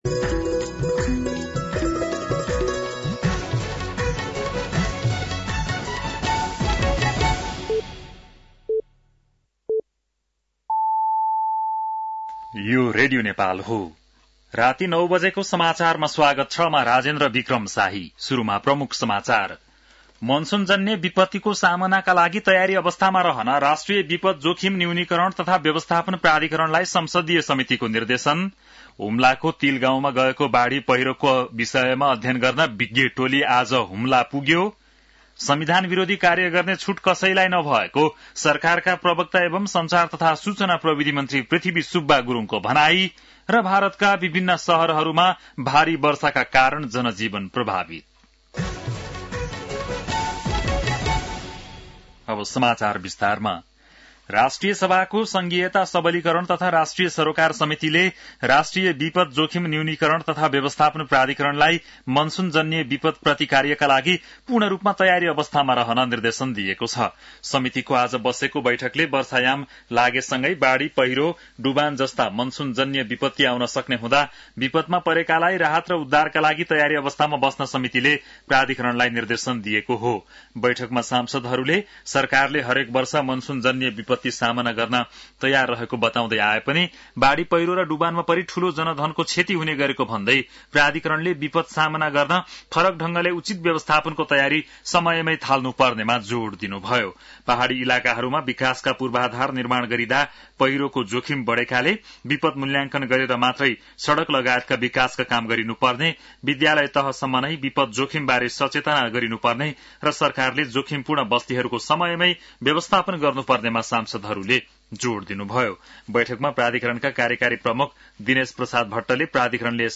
बेलुकी ९ बजेको नेपाली समाचार : ८ जेठ , २०८२
9-PM-Nepali-NEWS-1-2.mp3